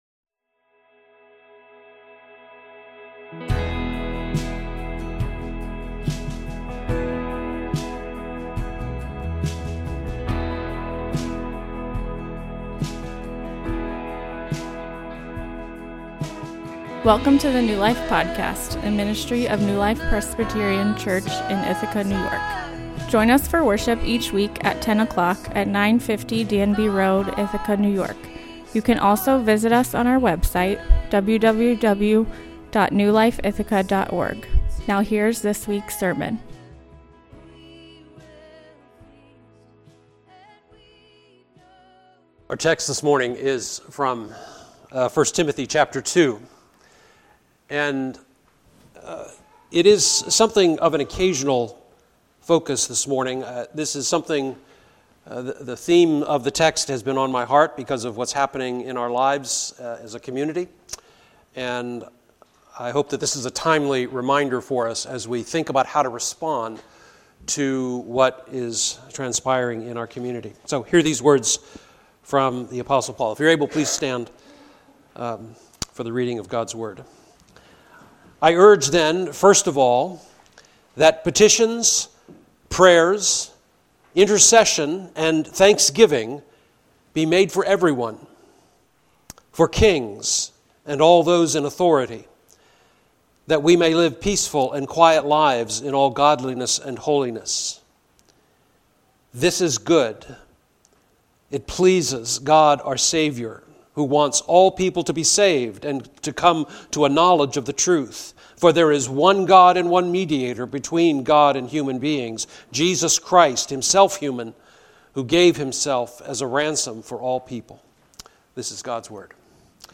A sermon on 1 Timothy 2:1-6